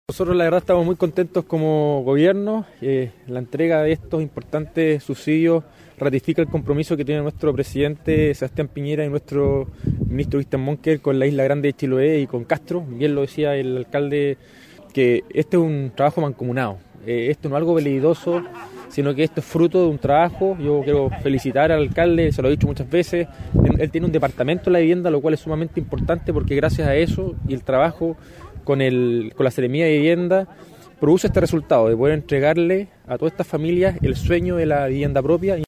Rodrigo Wainraihgt, seremi de Vivienda y Urbanismo